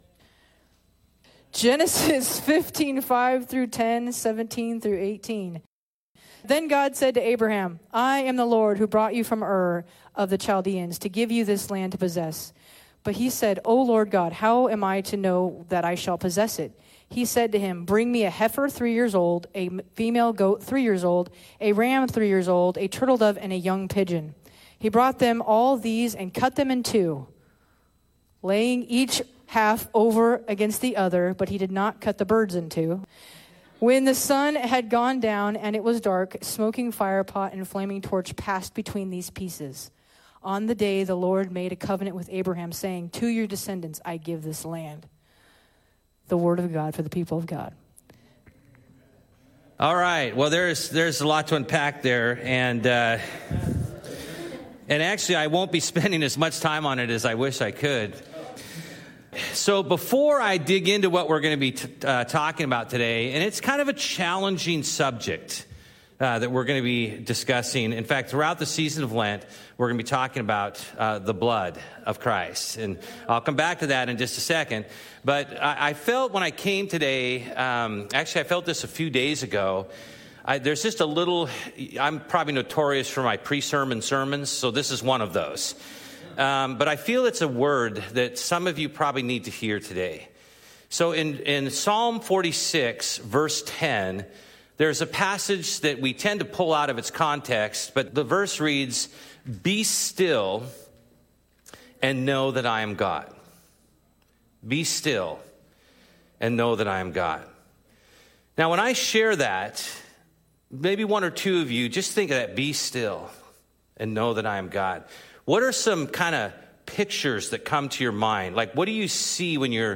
2022 Discovering the Mysteries of the Blood Preacher